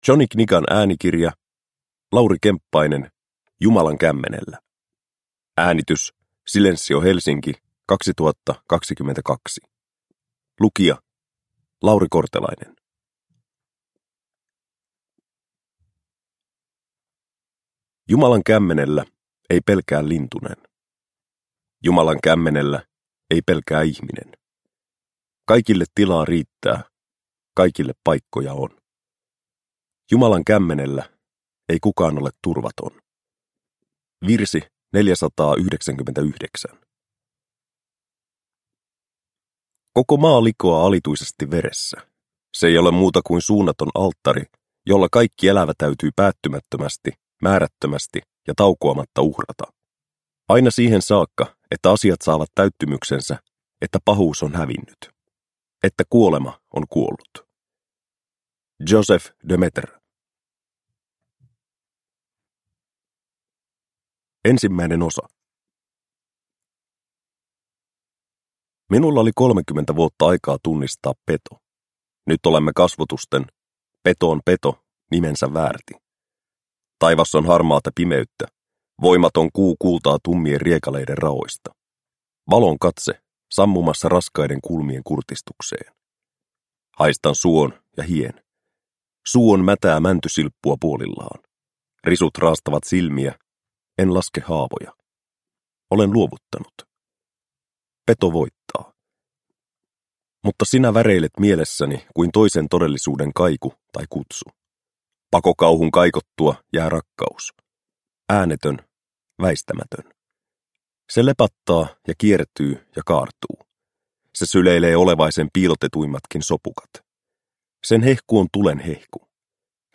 Jumalan kämmenellä – Ljudbok – Laddas ner